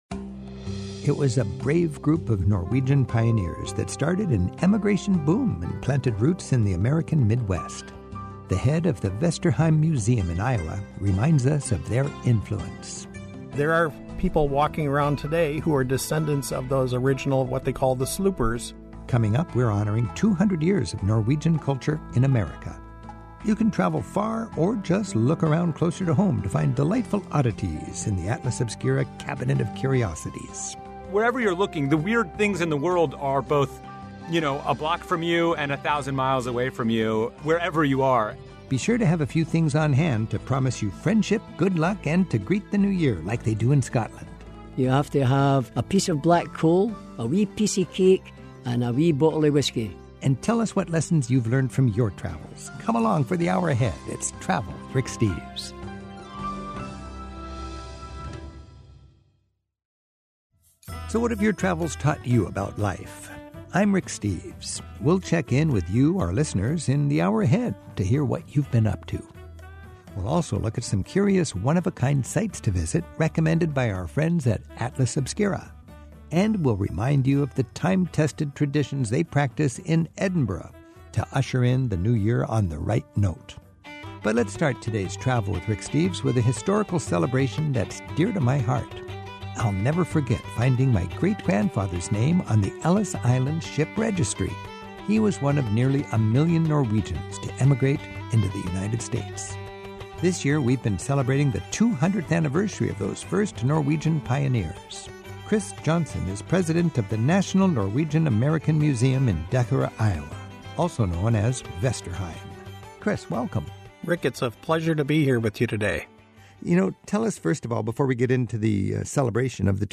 And listen in as callers share their favorite travel memories of the year behind us. Plus, learn from Scottish tour guides about Edinburgh's lively New Year's Eve traditions of Hogmanay.